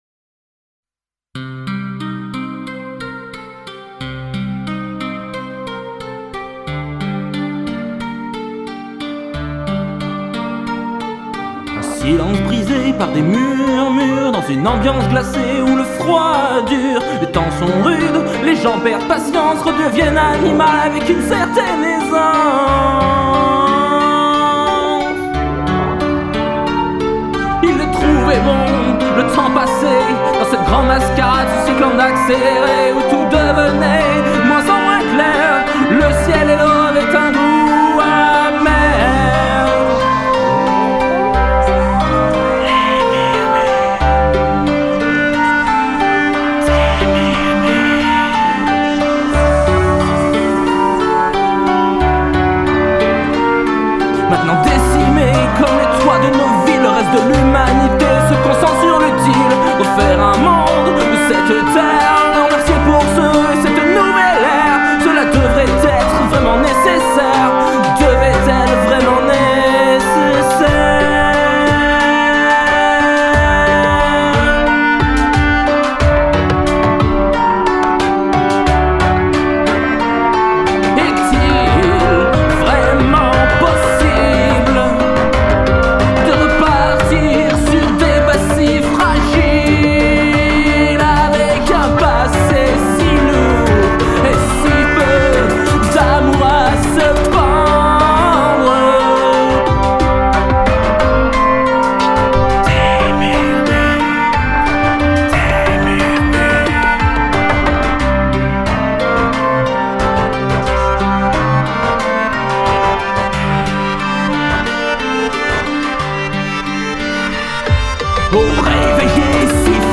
Cela me fait penser à une bande son mélancolique, en général je ne suis pas fan des musiques mélancolique car elles m’attristent mais c’est subjectif et je dois reconnaitre que votre compo est bien produite.